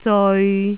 駿 eon